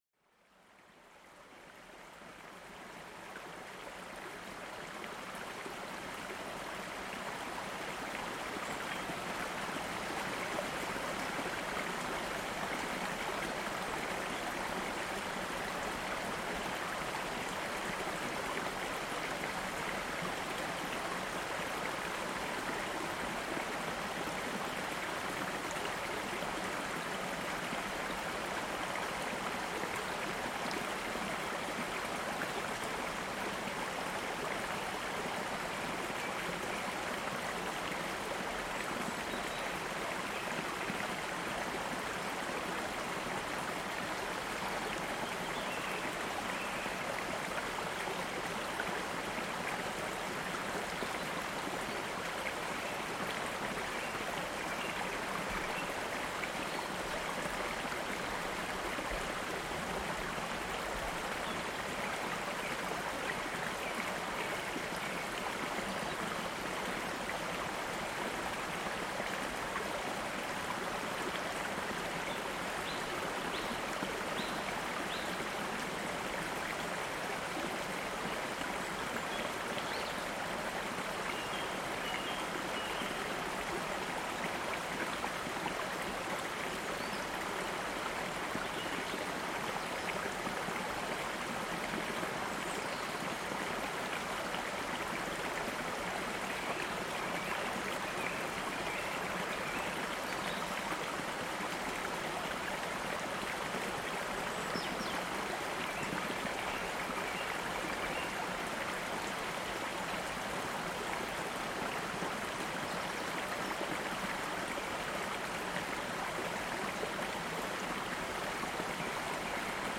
Déjate llevar por el sonido relajante de un gran río para una relajación profunda y un sueño reparador
Escucha las poderosas aguas de un río serpenteante que te transportan a un estado de total relajación. Los sonidos naturales capturan la esencia de la calma, ofreciendo una experiencia sonora inmersiva. Este episodio es perfecto para relajarse, aliviar el estrés y conciliar el sueño.Este podcast te ofrece una escapada a la naturaleza con sonidos auténticos que promueven la relajación.